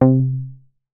MoogCar 004.WAV